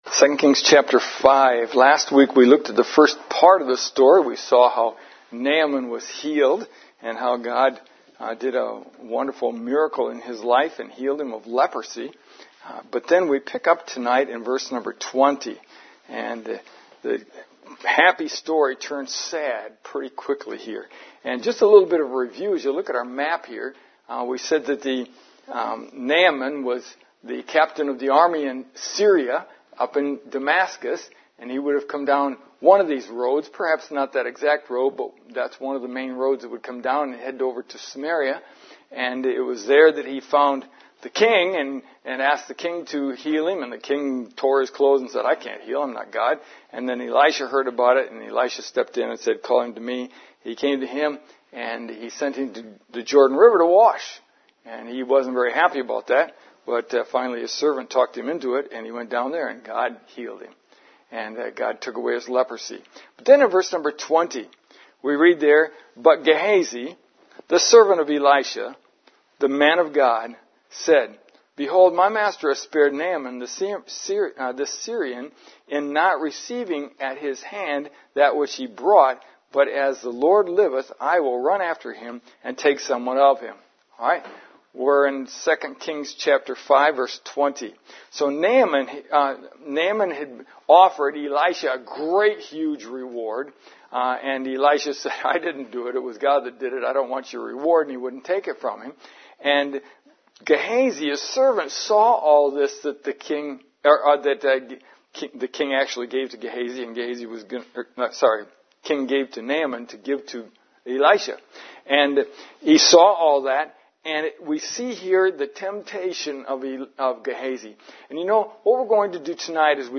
Elisha The Prophet of God Lesson 09 – Gehazi’s Greed 2 Kings 5:20-27